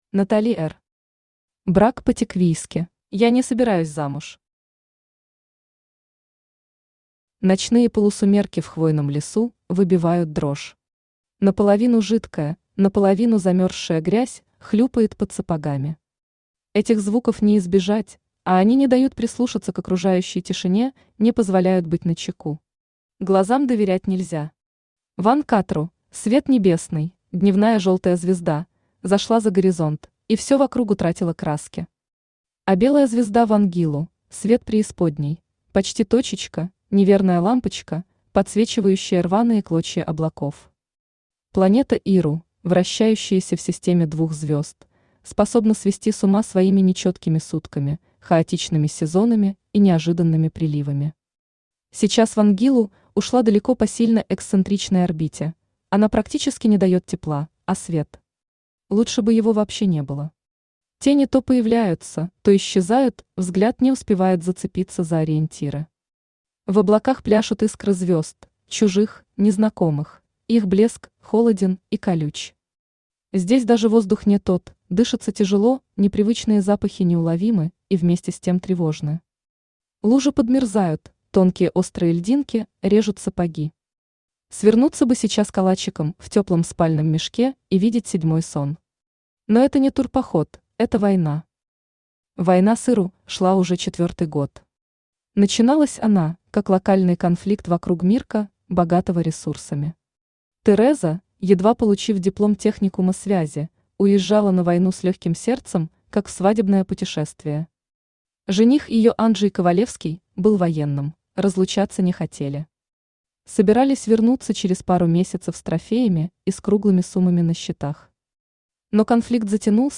Аудиокнига Брак по-тиквийски | Библиотека аудиокниг
Aудиокнига Брак по-тиквийски Автор Натали Р. Читает аудиокнигу Авточтец ЛитРес.